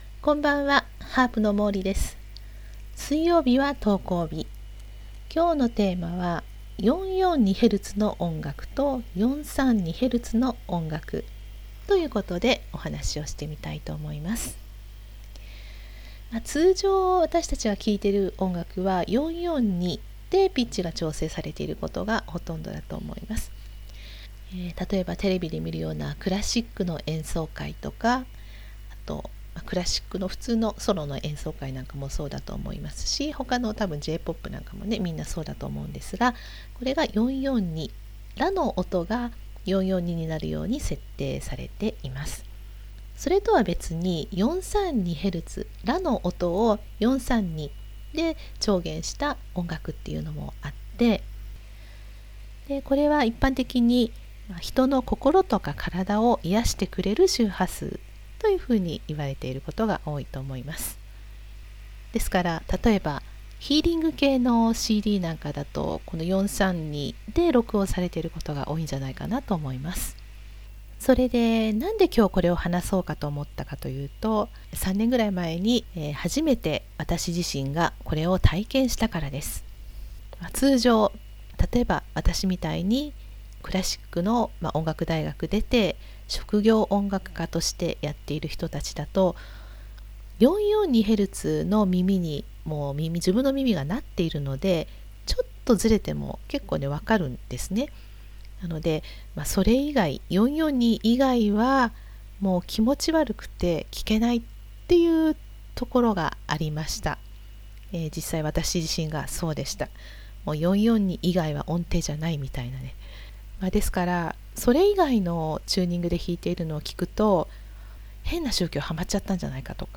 （音声ブログ）442Hzの音楽 432Hzの音楽